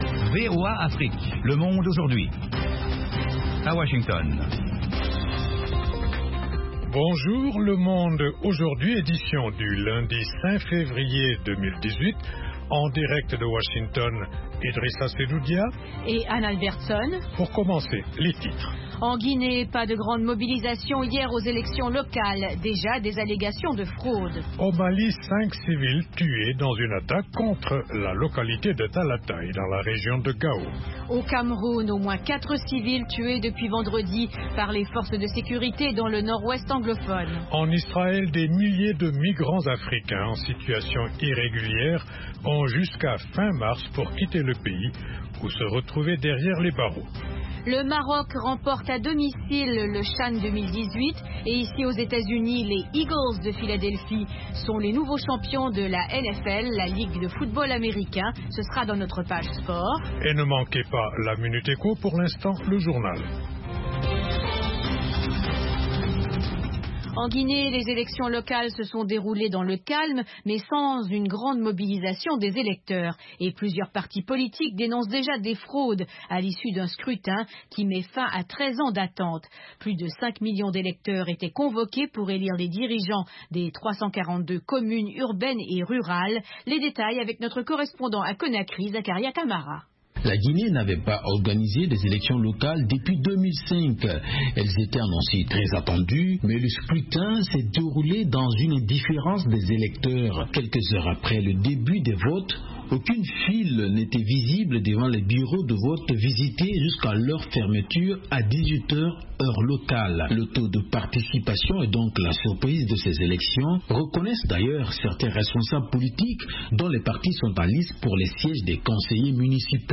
Interviews, reportages de nos envoyés spéciaux et de nos correspondants, dossiers, débats avec les principaux acteurs de la vie politique et de la société civile. Le Monde Aujourd'hui vous offre du lundi au vendredi une synthèse des principaux développementsdans la région.